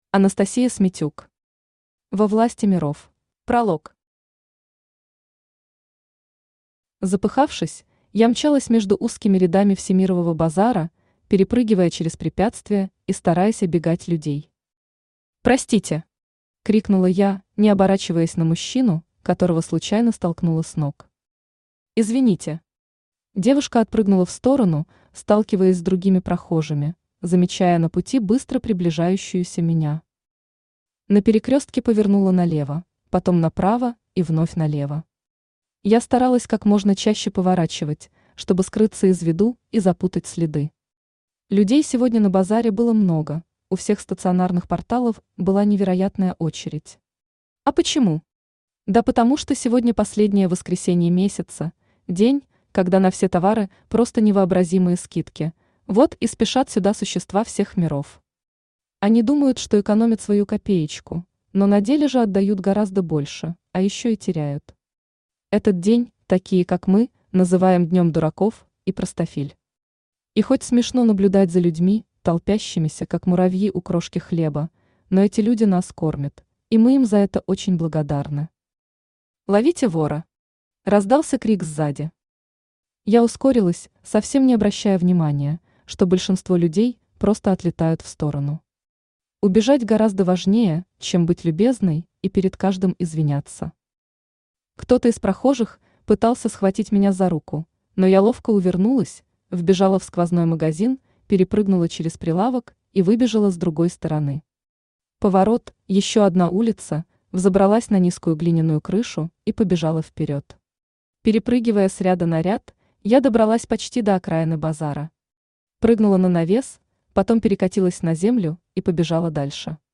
Аудиокнига Во власти Миров | Библиотека аудиокниг
Aудиокнига Во власти Миров Автор Анастасия Смитюк Читает аудиокнигу Авточтец ЛитРес.